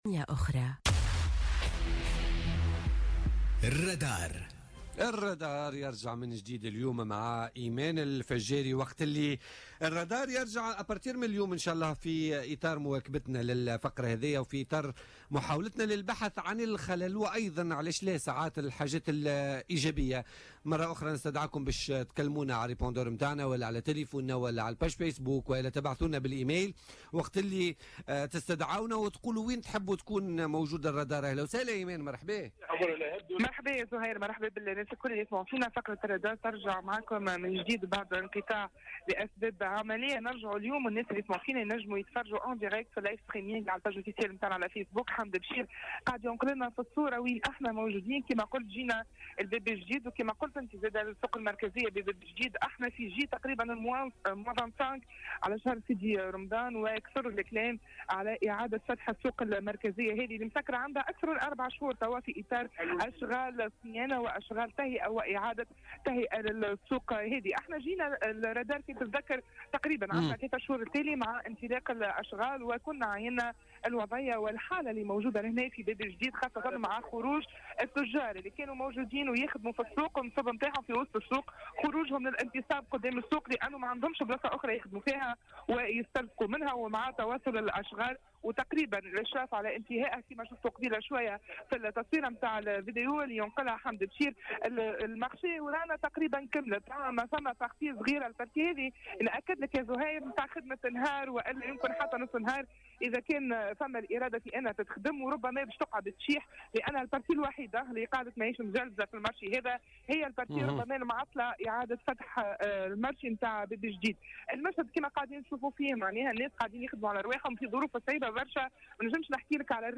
تنقل الرادار اليوم الثلاثاء 2 ماي 2017 إلى السوق المركزية بباب الجديد سوسة الذي يتواصل اغلاقها منذ 4 أشهر في ظل انتصاب التجار في الشارع أمامها.